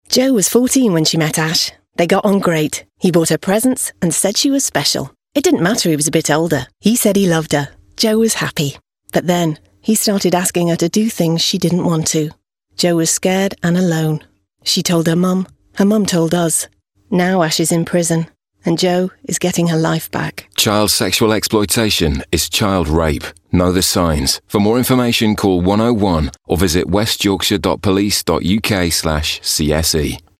West Yorkshire Police Child Sexual Exploitation Campaign - 'Know The Signs' RADIO ADVERT